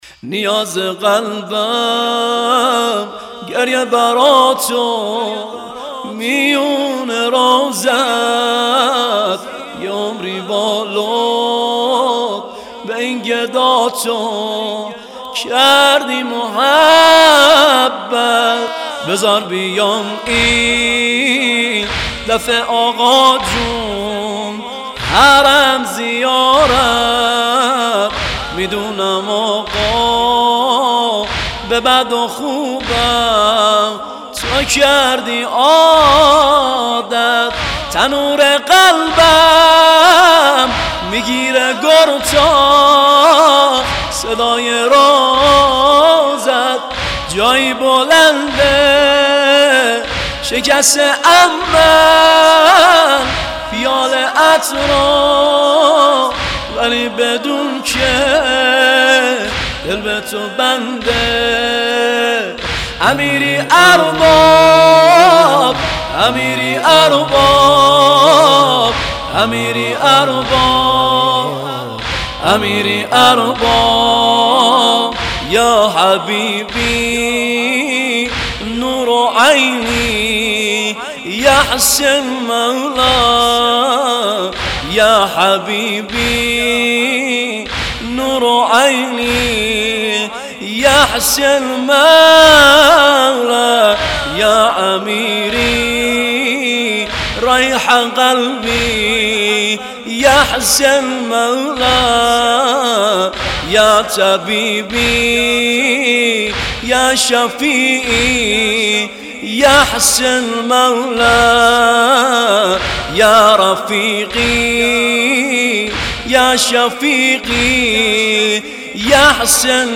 نوحه زنجیر زنی